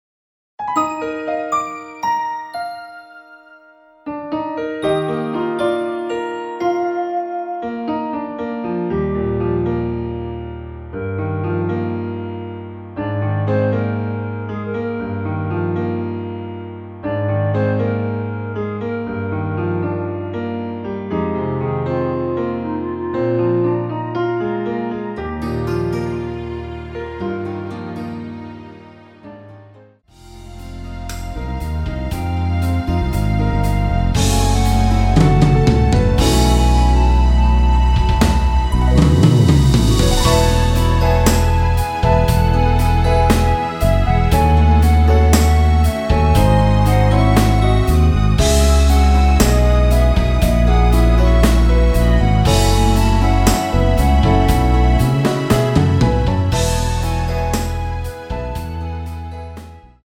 *부담없이즐기는 심플한 MR~
원키에서(-1)내린 멜로디 포함된 MR입니다.
Eb
앞부분30초, 뒷부분30초씩 편집해서 올려 드리고 있습니다.
중간에 음이 끈어지고 다시 나오는 이유는